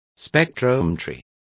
Complete with pronunciation of the translation of spectrometry.